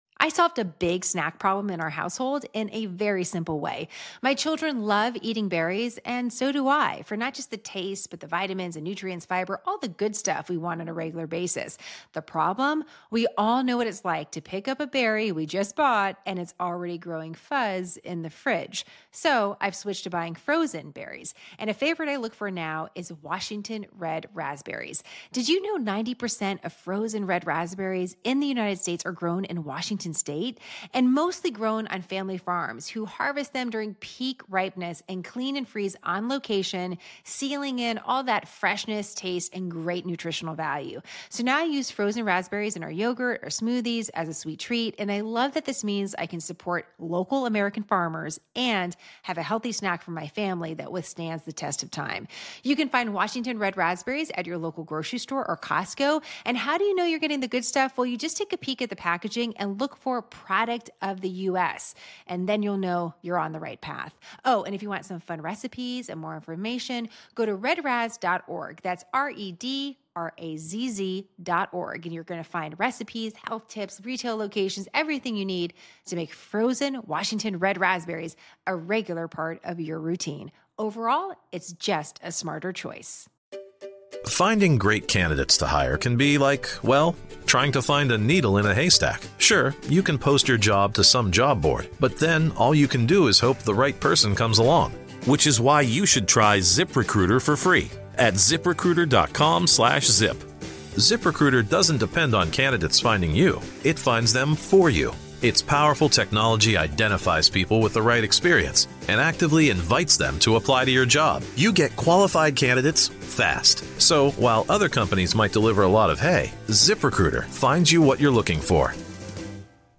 INTERVIEW: Strokes, Secret Surgeries, Depression & Hemorrhoids (Yes, Hemorrhoids) - What To Know About The Health of U.S. Presidents